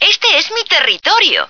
flak_m/sounds/female2/est/F2myhouse.ogg at 602a89cc682bb6abb8a4c4c5544b4943a46f4bd3